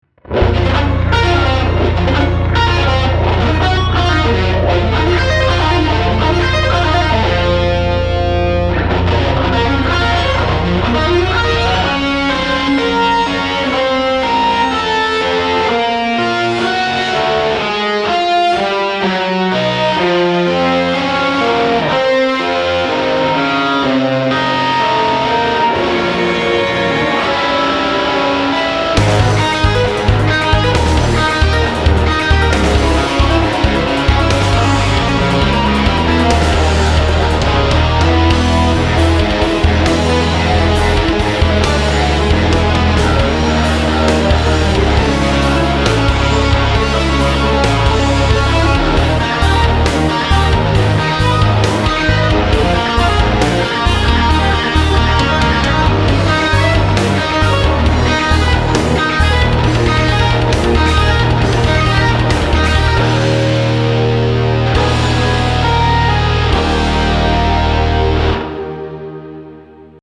Neoclassical Metal Composition
These etudes use shred guitar techniques like sweep picking, hybrid picking and legato.